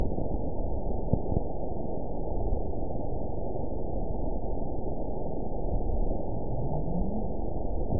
event 920429 date 03/24/24 time 23:44:29 GMT (1 year, 1 month ago) score 9.58 location TSS-AB01 detected by nrw target species NRW annotations +NRW Spectrogram: Frequency (kHz) vs. Time (s) audio not available .wav